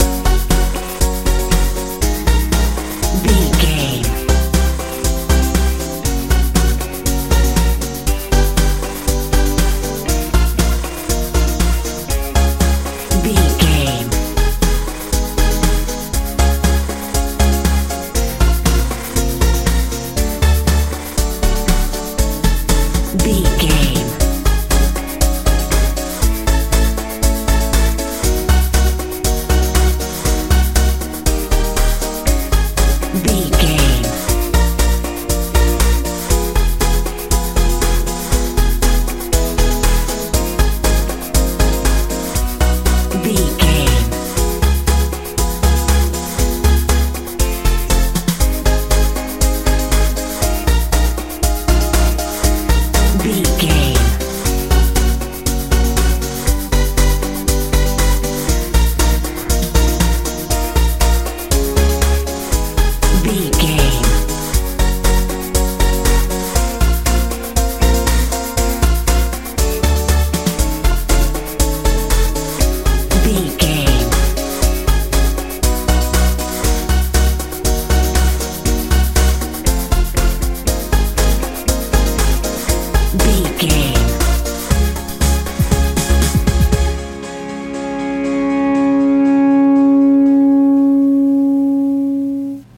euro dance feel
Ionian/Major
F♯
joyful
fun
electric guitar
synthesiser
bass guitar
drums
80s
90s